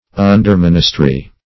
Search Result for " underministry" : The Collaborative International Dictionary of English v.0.48: Underministry \Un`der*min"is*try\, n. A subordinate or inferior ministry.